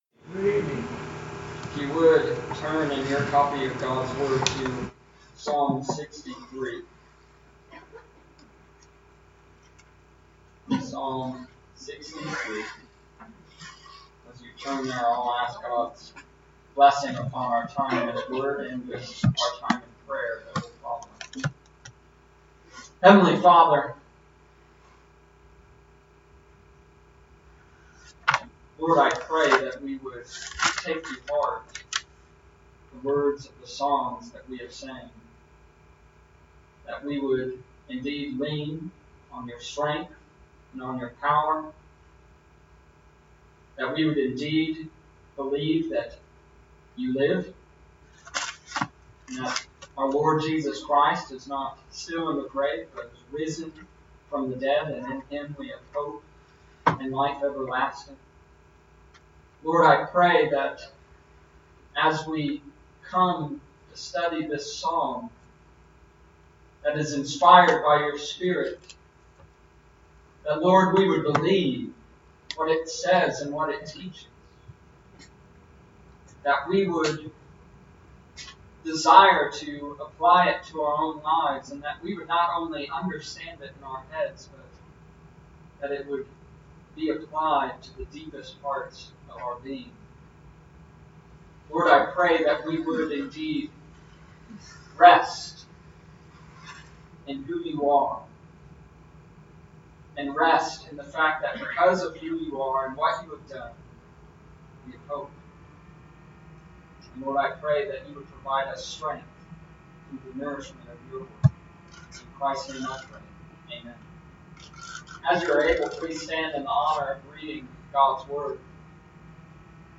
Psalm Chapter 6 verses 1-11 March 6th, 2019 Wednesday night service